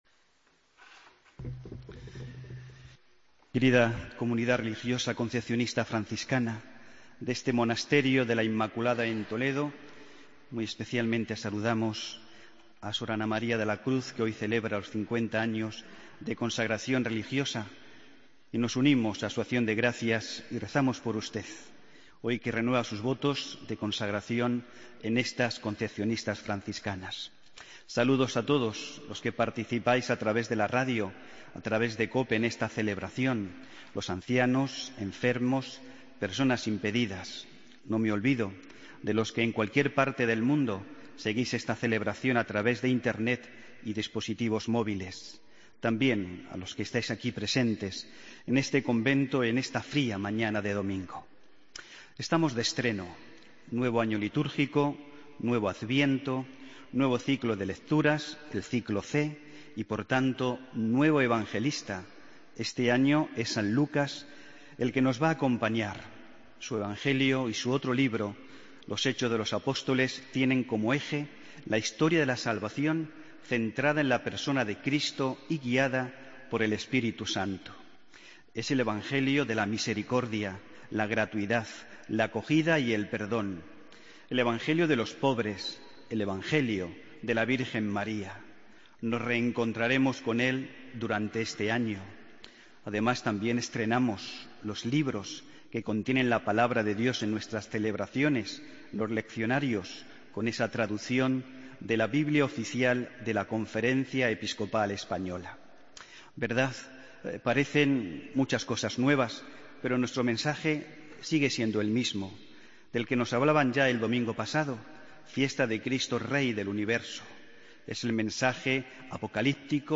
Homilia-del-domingo-29-de-noviembre